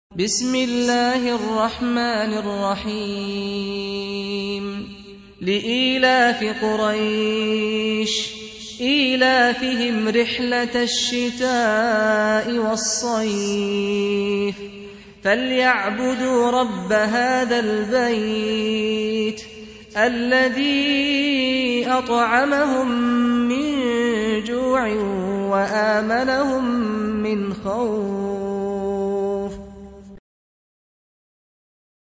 Surah Repeating تكرار السورة Download Surah حمّل السورة Reciting Murattalah Audio for 106. Surah Quraish سورة قريش N.B *Surah Includes Al-Basmalah Reciters Sequents تتابع التلاوات Reciters Repeats تكرار التلاوات